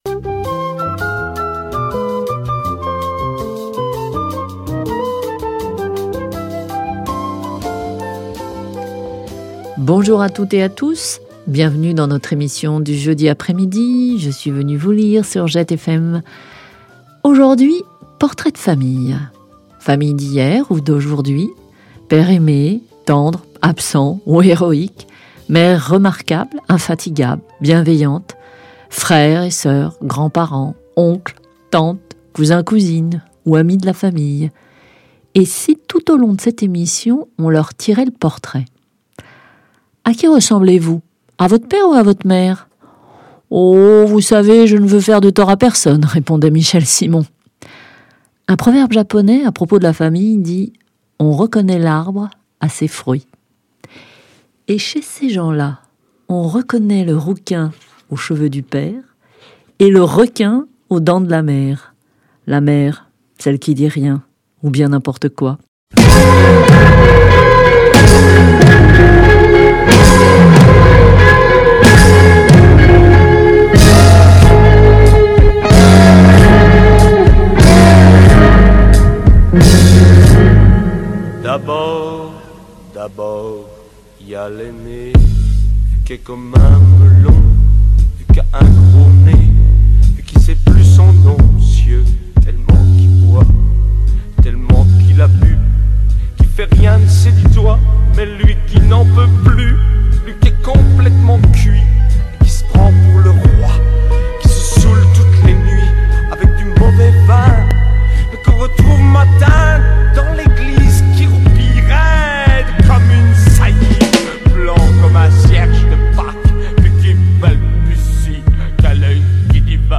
Choix des textes et lectures
Programmation musicale et réalisation